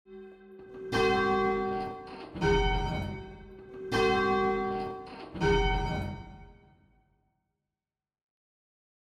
To our western musical ears, they produce sounds which are neither pleasing nor displeasing…. and perhaps a little bland!
Perfect 8ve.
An Octave interval
The notes share the same letter name.